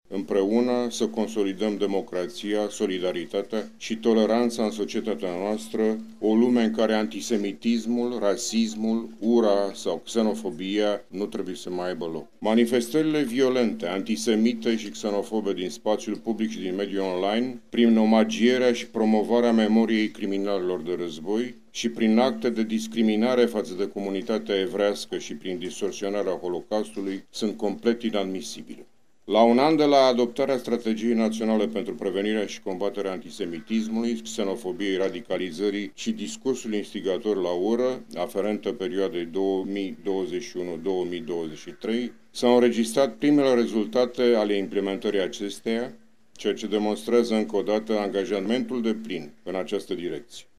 Astăzi la Iaşi sunt comemorate victimele Pogromului din 1941.
Președintele României, Klaus Iohannis a transmis un mesaj despre nevoia de consolidare a democrației și toleranței în societatea noastră. Mesajul a fost citit de consilierul de stat în Departamentul Securității Naționale – Constantin Ionescu, Directorul Oficiului Informații Integrate: